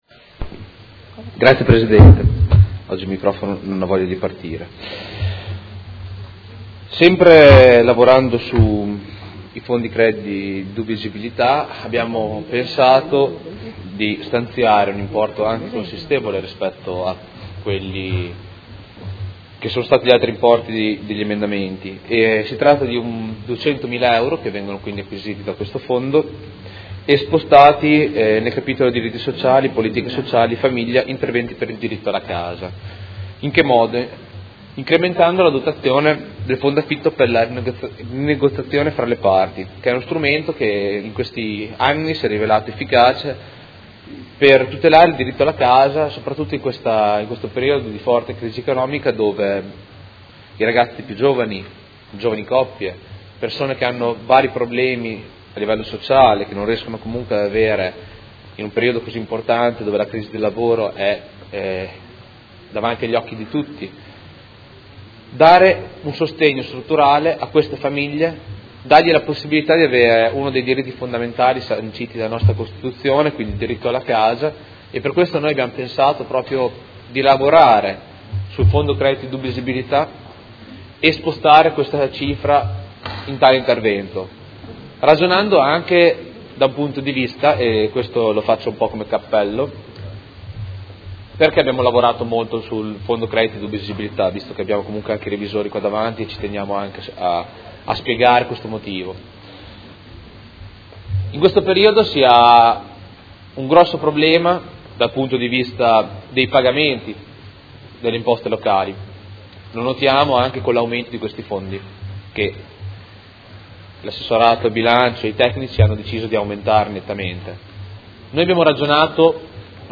Seduta del 25 febbraio. Approvazione Bilancio: presentazione emendamento Prot. 21347